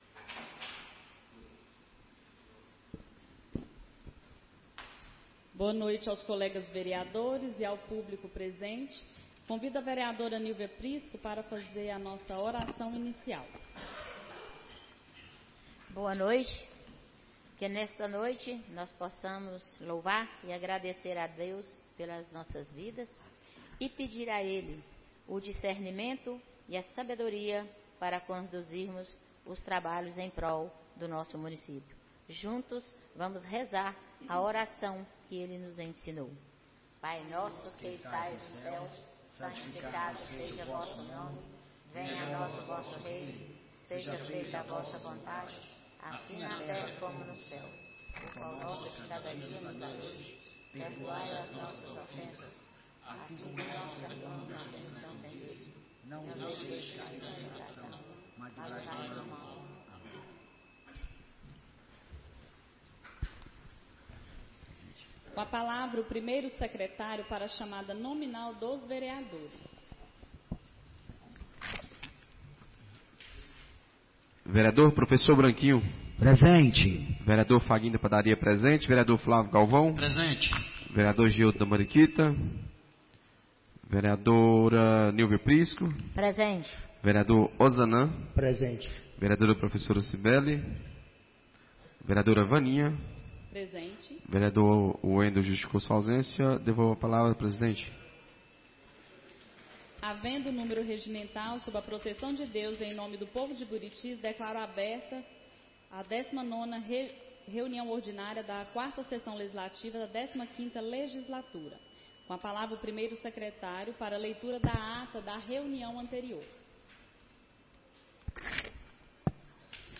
19ª Reunião Ordinária da 4ª Sessão Legislativa da 15ª Legislatura - 10-06-24